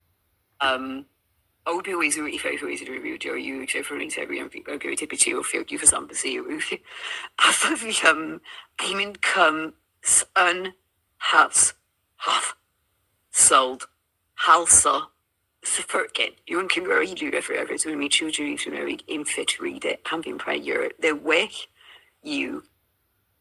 I wondered if some of it was Welsh? 2025-02-22 12:52:11 EST Source